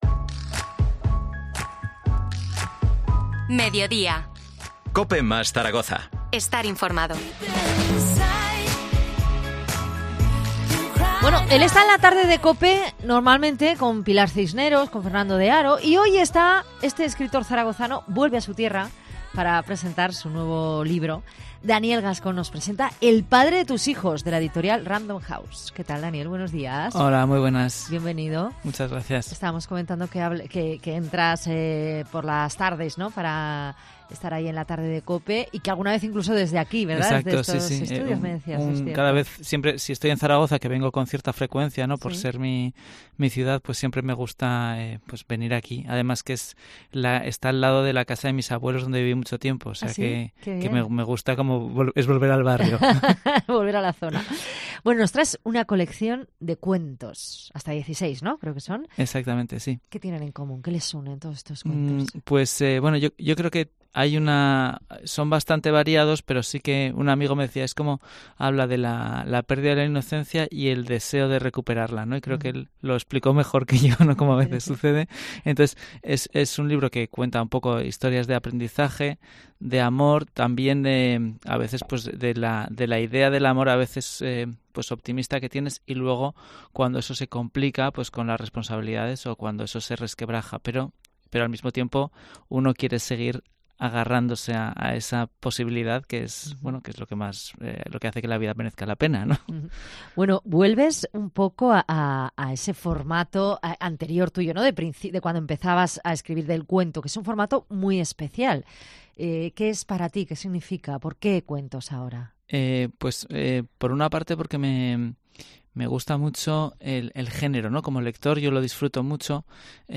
Entrevista al escritor zaragozano Daniel Gascón, sobre su último libro 'El padre de tus hijos'.